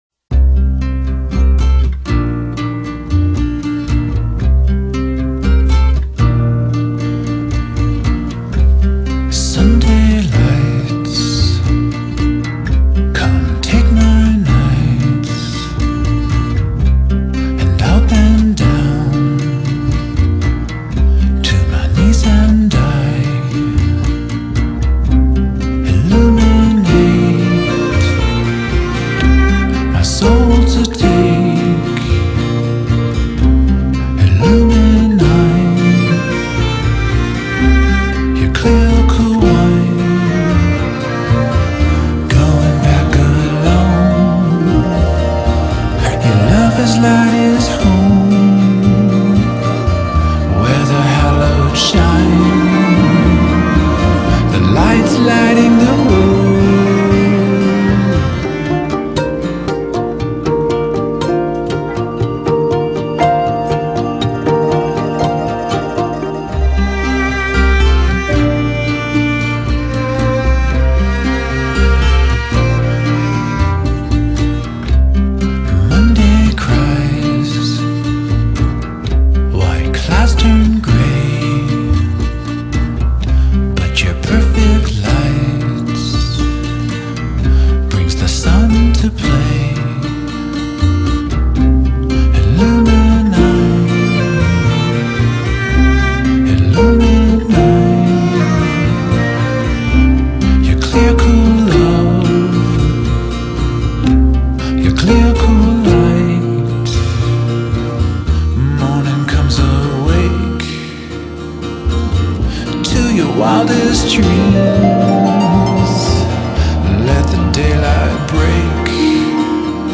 Tags2010s 2011 Eastern US Rock